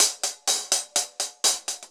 Index of /musicradar/ultimate-hihat-samples/125bpm
UHH_AcoustiHatC_125-02.wav